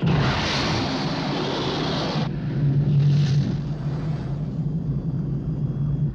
BSG FX - Viper Launch 02 Download Picture
BSG_FX-Viper_Launch_02.wav